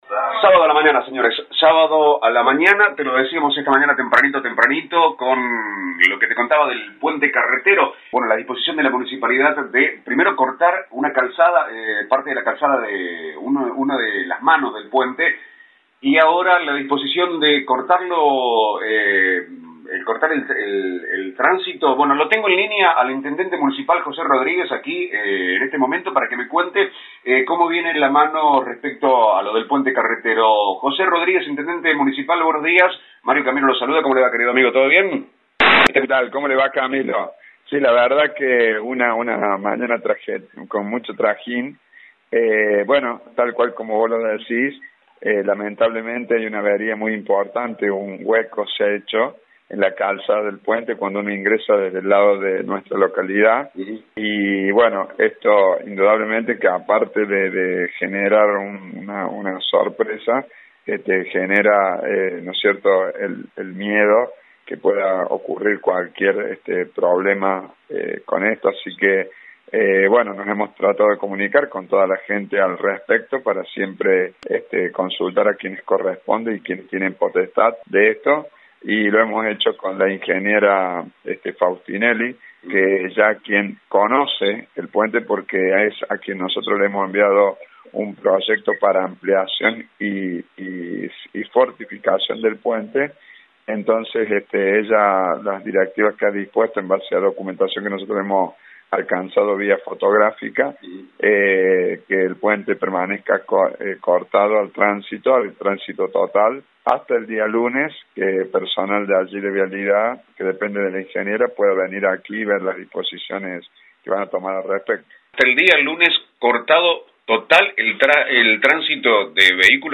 Audio nota de FM Villa Santa Rosa
Intendente-Municipal-Rodrigues-PUENTE-CORTADO-.mp3.mp3